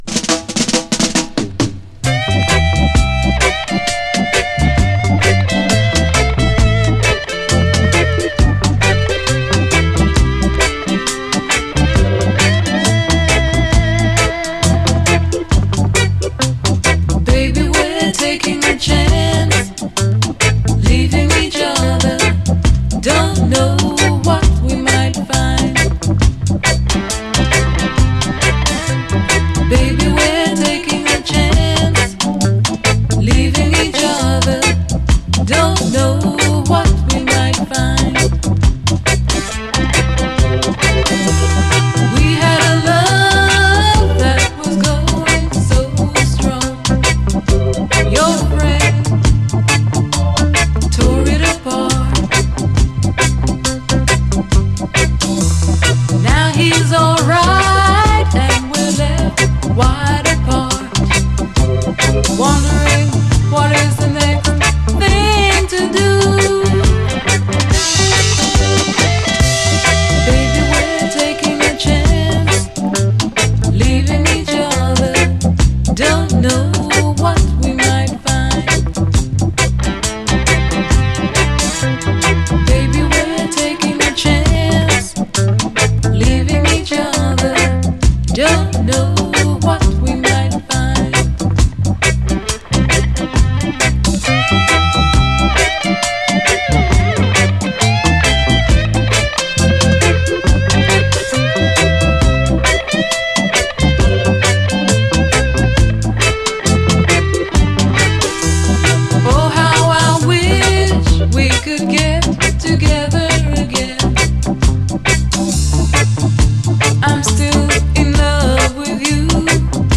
REGGAE
ギラついたギターが切なく響く哀愁スウィート・レゲエ〜黎明期UKラヴァーズ！
フワフワしたヴォーカル＆コーラスの処理など確実に後のUKラヴァーズへと繋がる甘酸っぱいサウンドを実感できます。